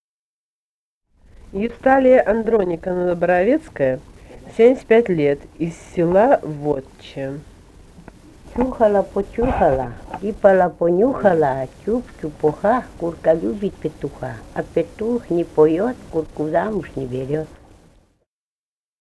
«Чухала, почухала» (прибаутка).